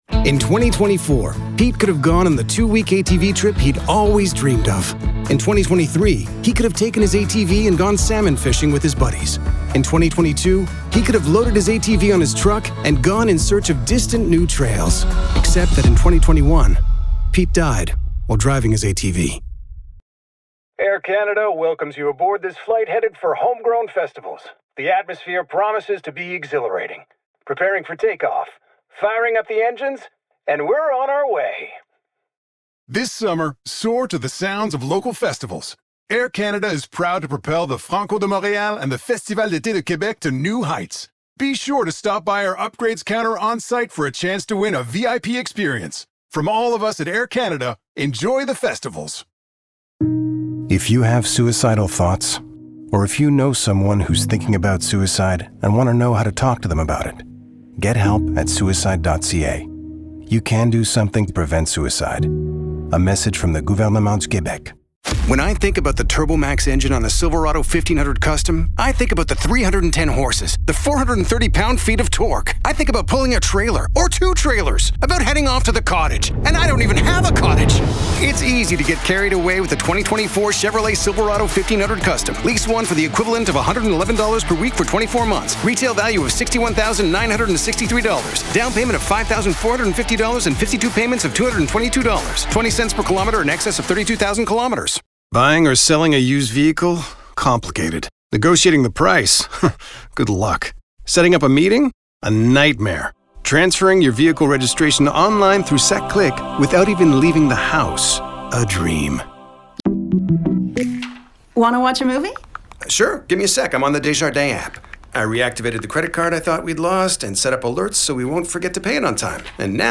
Commercials - EN